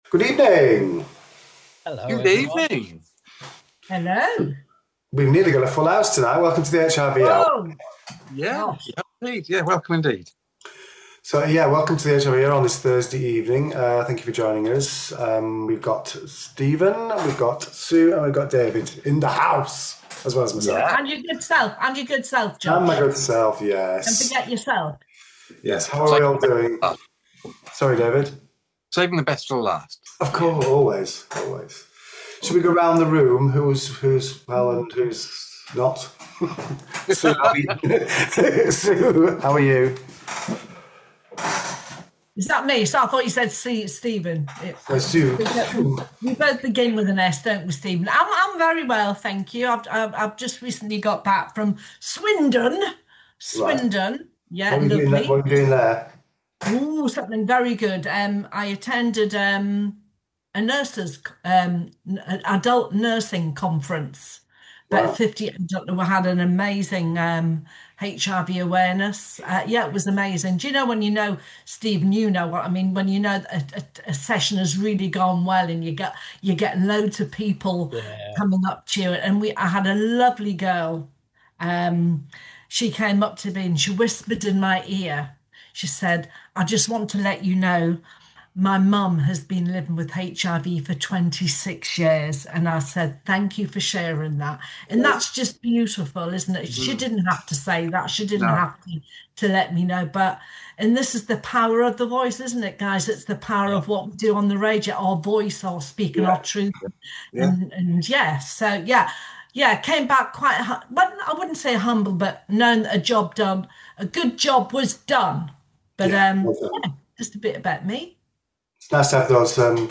HIV News and Great music too!